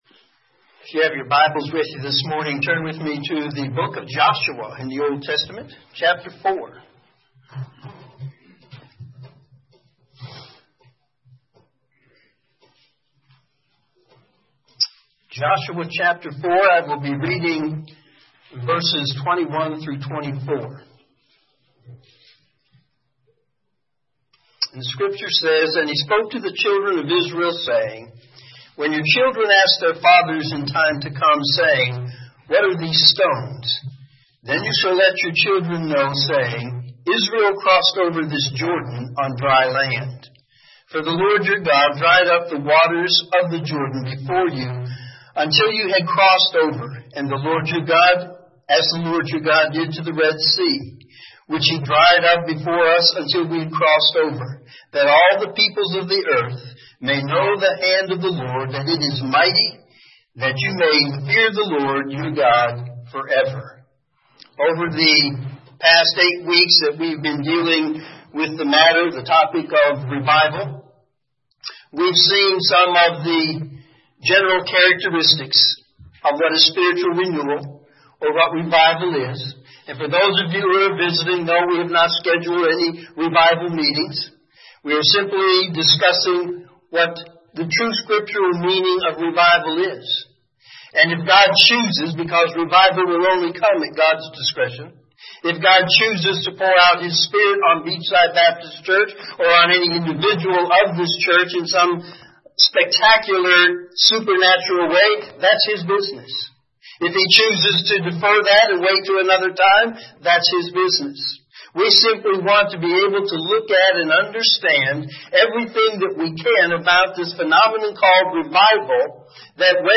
Morning Sermon
sermon3-5-17.mp3